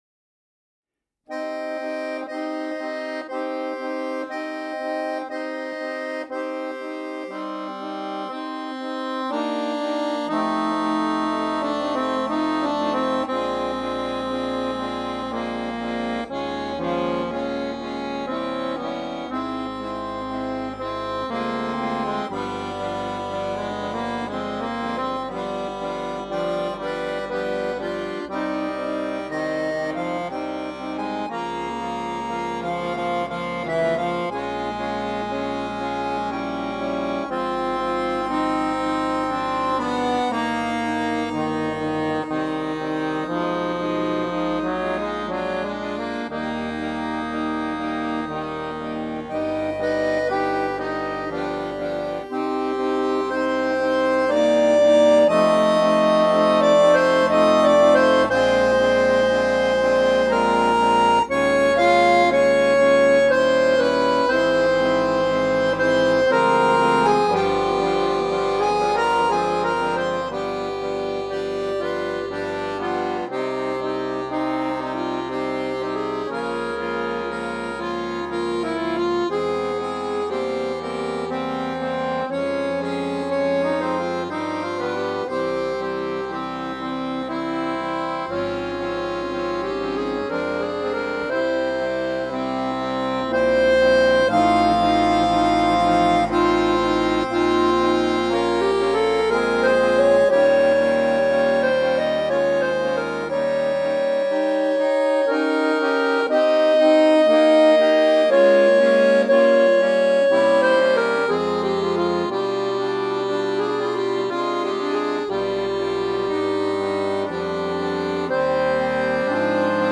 Compositions and Arrangements for Accordion Orchestra
Before that: Added a new arrangement, for accordion quintet, of Après un Rêve by Gabriel Fauré.